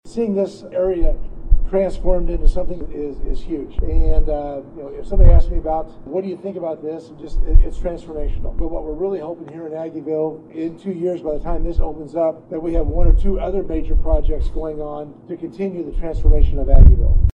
City officials, contractors, developers, employees, and more gathered Thursday in the parking lot behind Kite’s Bar and Grill for the official groundbreaking of the Midtown Development project.